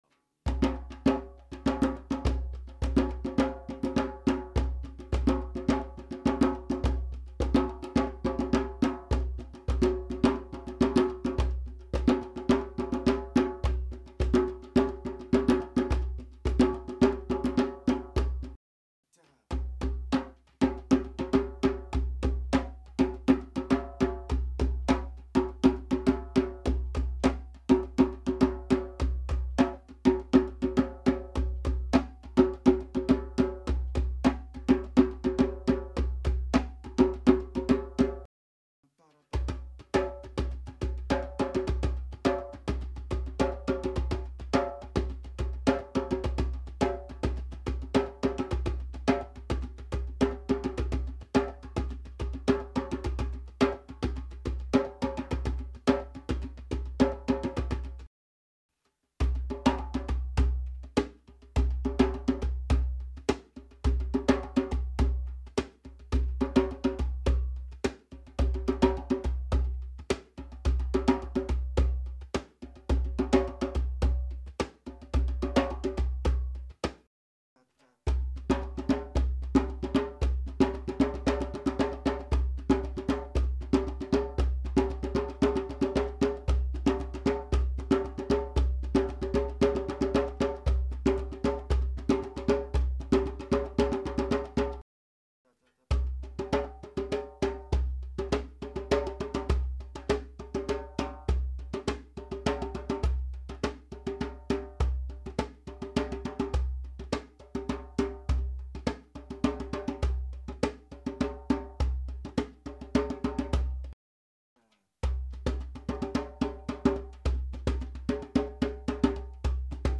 6 Drum Circle Rhythm Samples
There's no talking, or fluff.